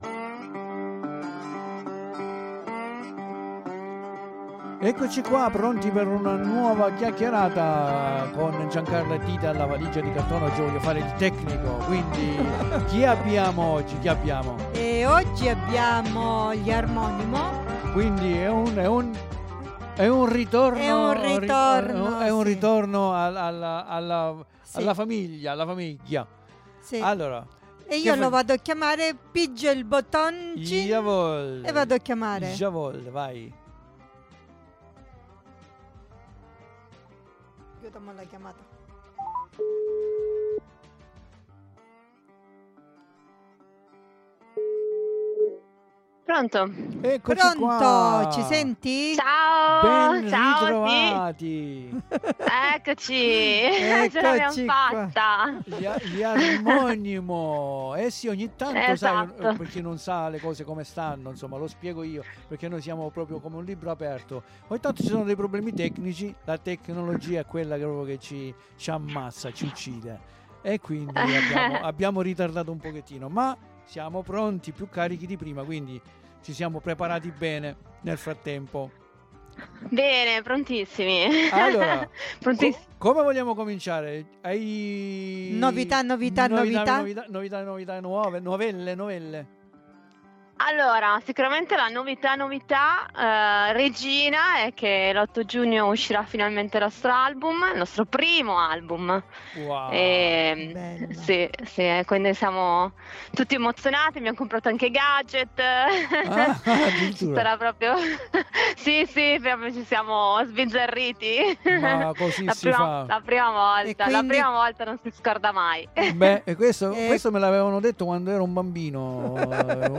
95651_INTERVISTA_Armonymo.mp3